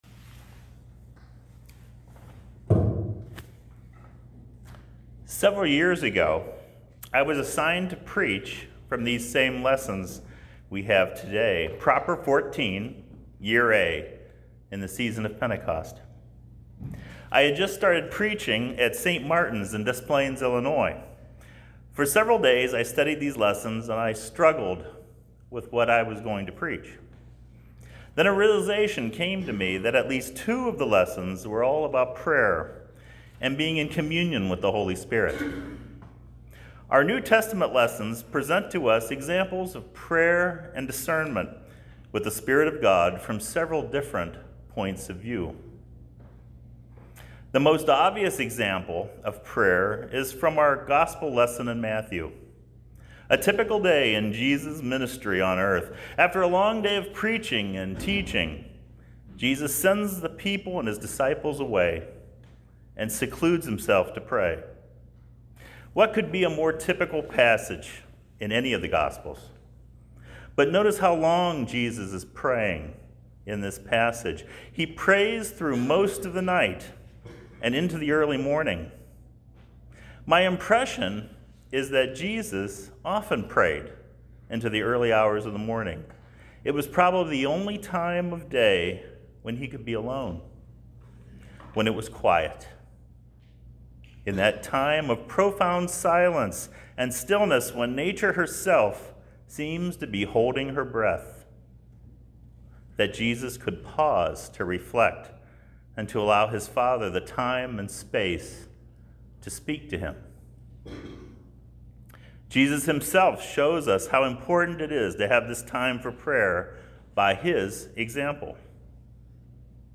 I was privileged to give this sermon today at the Episcopal churches, Saint John’s, Lancaster, OH and Saint Paul’s, Logan, OH.